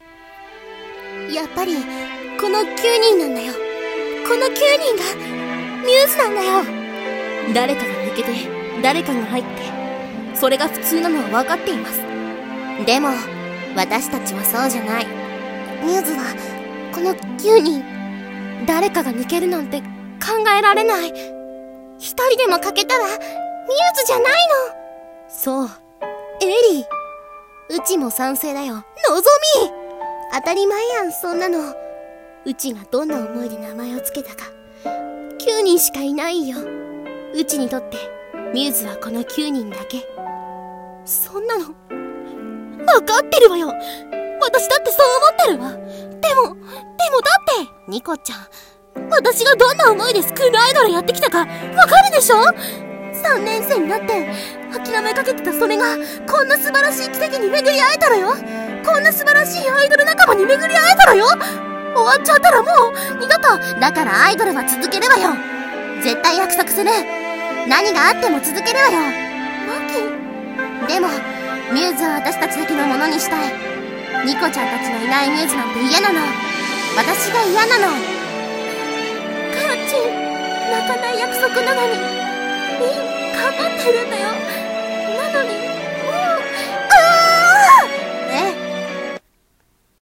2期11話 私たちが決めたこと 声劇用